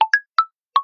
Seventh_Chords.ogg